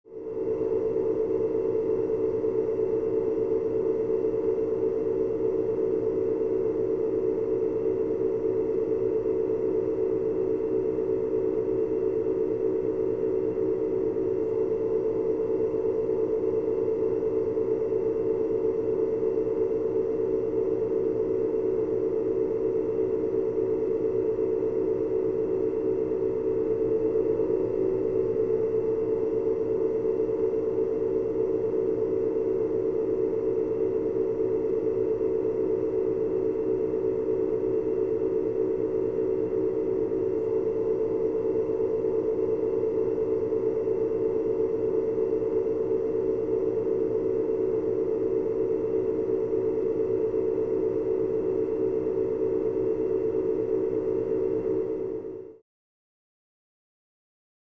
Submarine Ambience
Ship's Ventilation Noise And Low Hum.